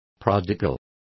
Complete with pronunciation of the translation of prodigal.